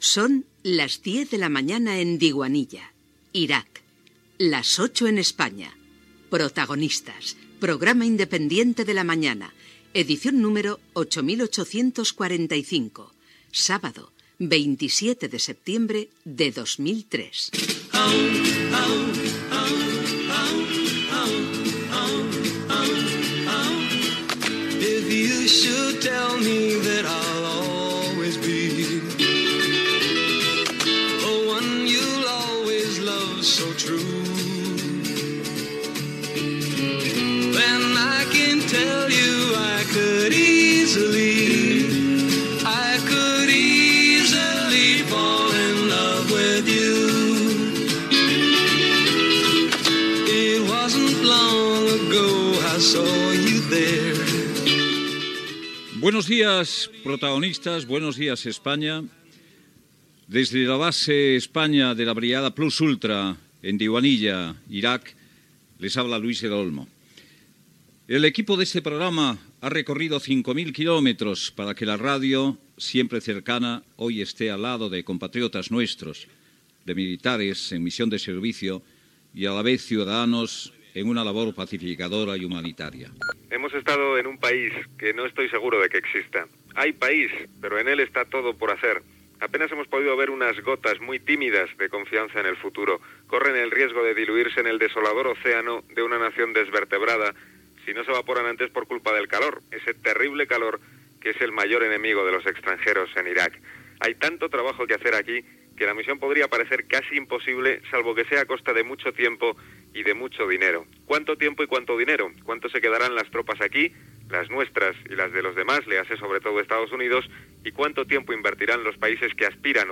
Fragment d'una edició especial del programa feta des de la base España de la brigada Plus Ultra a Diwaniya a l'Iraq.
Info-entreteniment